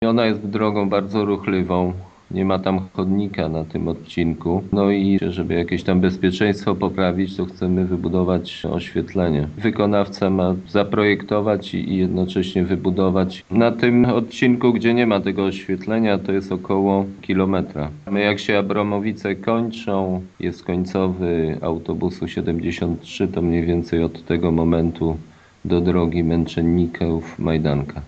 Pierwsza inwestycja dotyczy oświetlenia w Kalinówce. Trasa Lublin – Abramowice Prywatne – Kalinówka to bardzo ważna trasa powiatowa nie tylko w gminie, ale całym powiecie lubelskim – podkreśla wójt Jacek Anasiewicz: